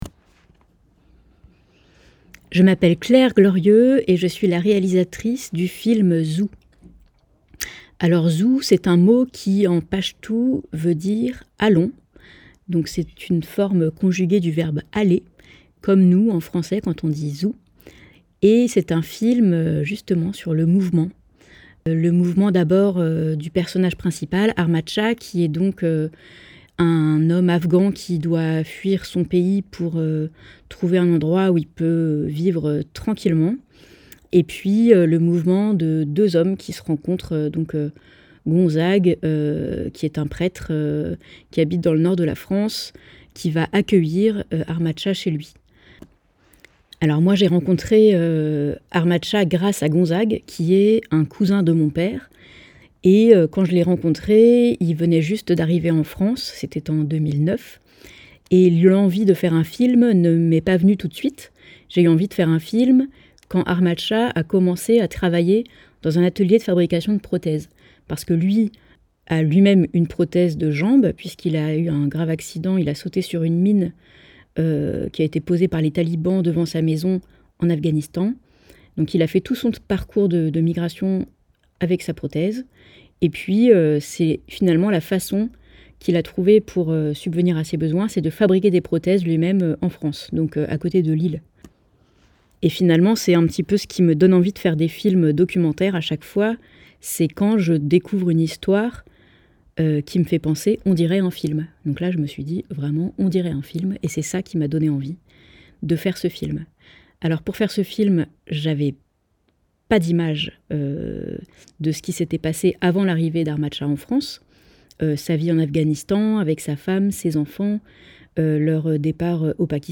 Ecoutez les réalisateur·ices vous parler de leurs films